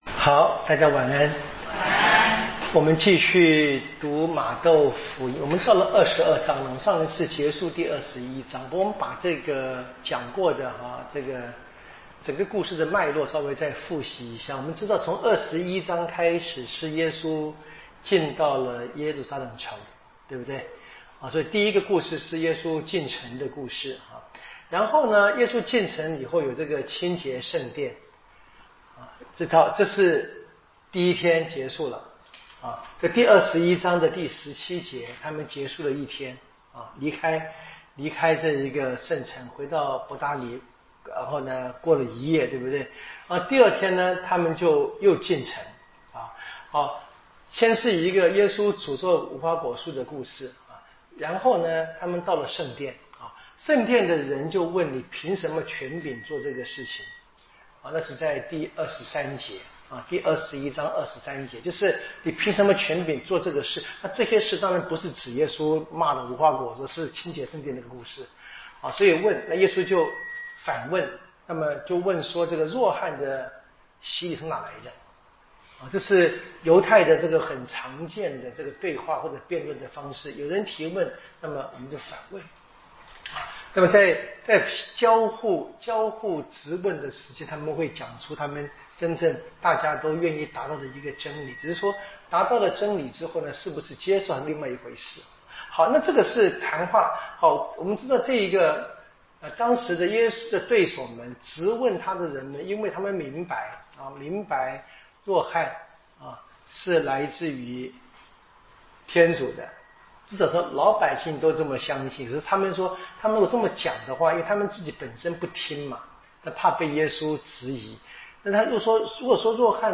【圣经讲座】《玛窦福音》